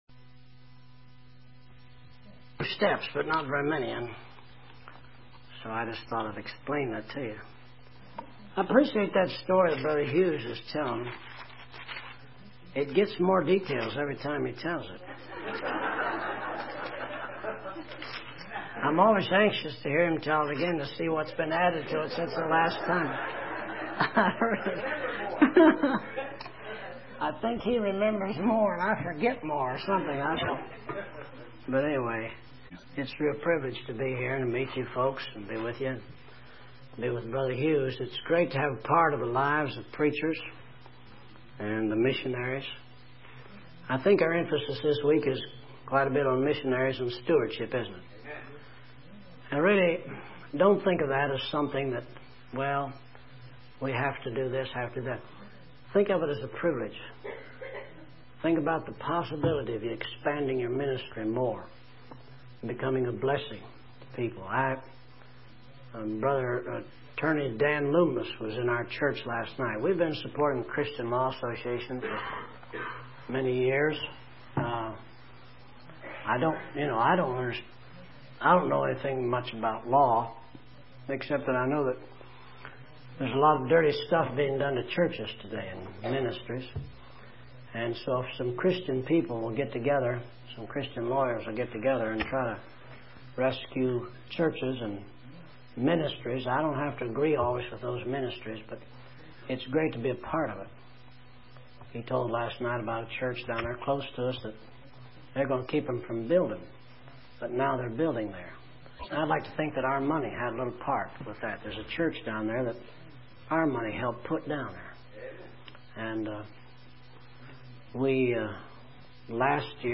Some things you need to learn- audio message.
We discovered this message on a cassette tape and had it reformatted to mp3 for your enjoyment.